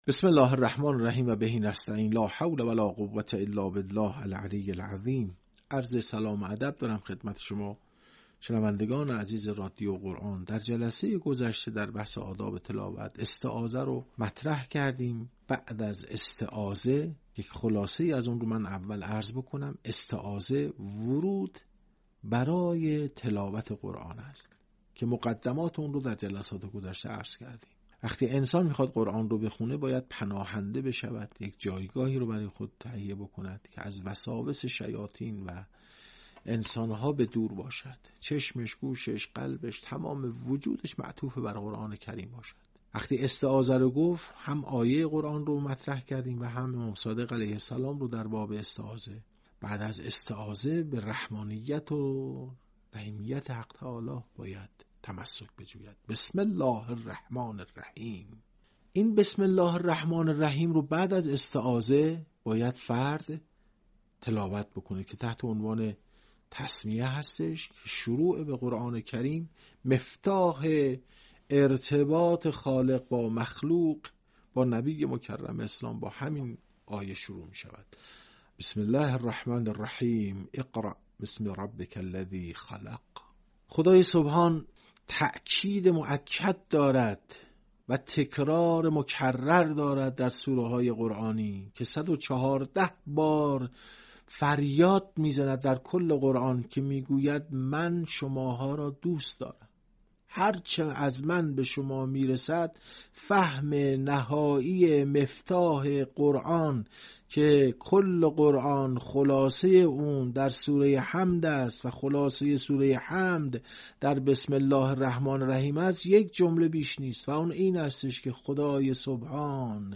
آداب تلاوت